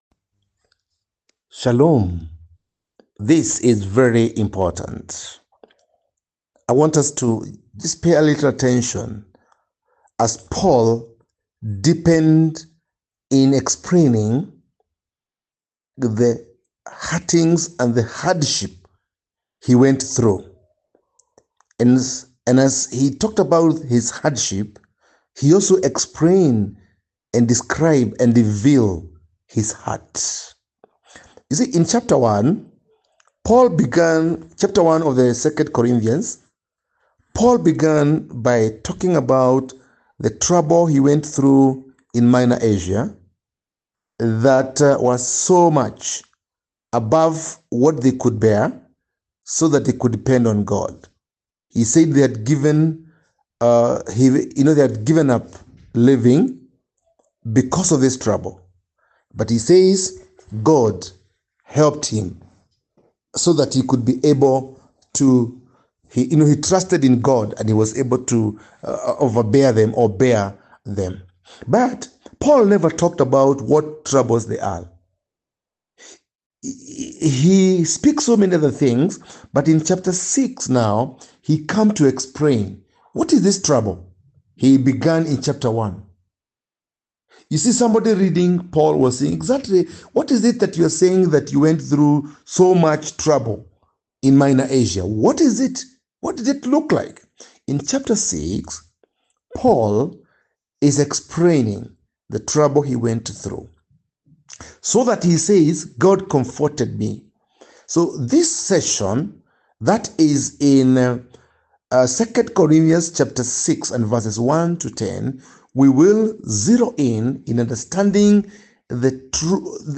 Post Lesson Teaching Summary